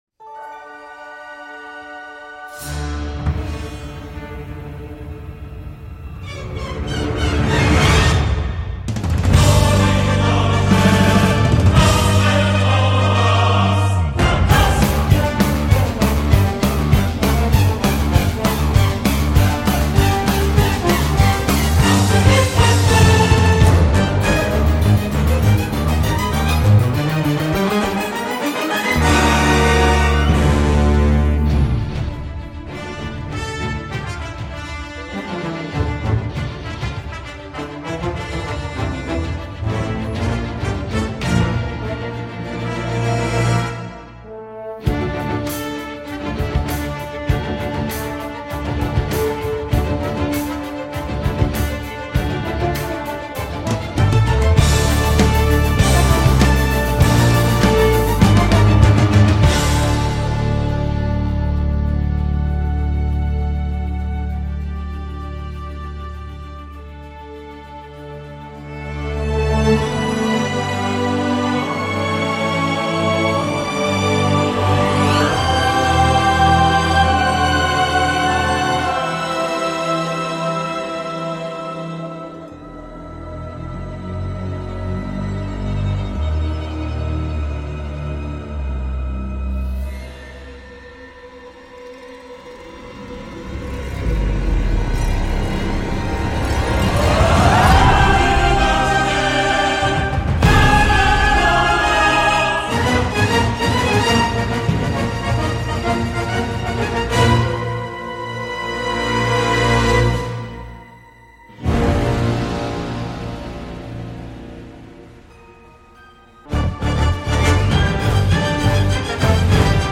Des chœurs, des idées mélodiques, du punch.